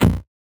CS_VocoBitB_Hit-12.wav